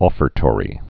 fər-tôrē, ŏfər-)